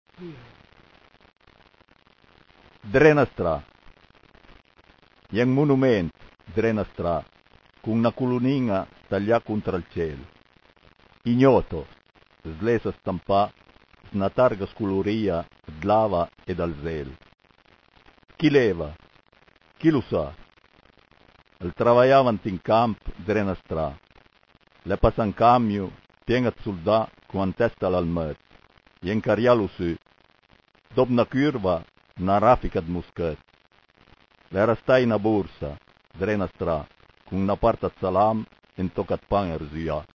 cliché chi par sénti la puizìa recità da l'autùr